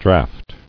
[draft]